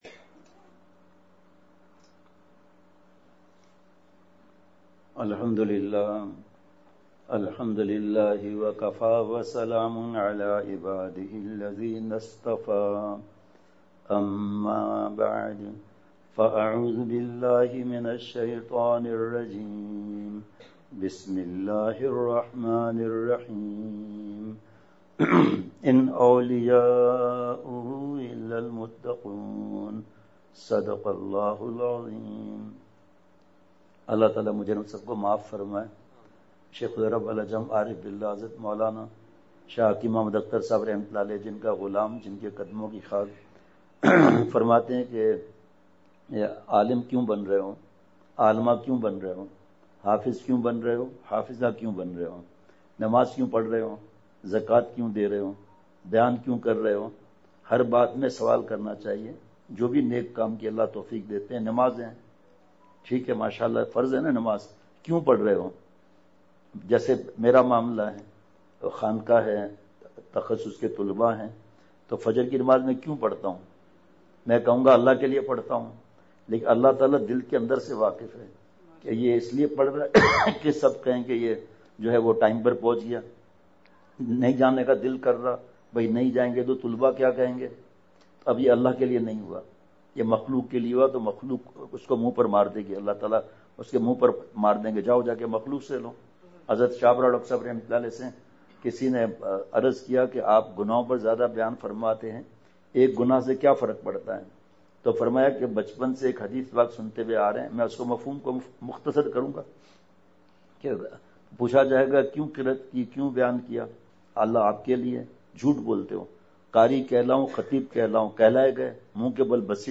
*بمقام: مدرسہ اسلامیہ اقراء ٹاون دنیا پور ملتان *